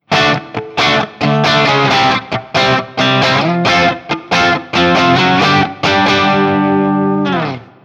JCM-800
I recorded this guitar using my Axe-FX II XL+, direct into my Macbook Pro using Audacity.
Since there is only one pickup and thus no pickup selector switch, the recordings are each of the one pickup with the knobs on 10.